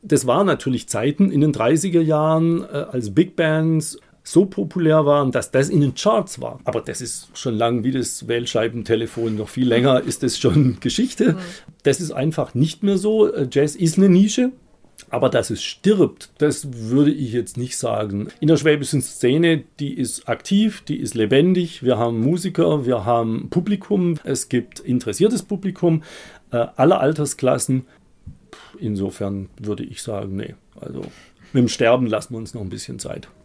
Musik
Aufgezeichnet im Tübinger Jazzclub im Juli 2018.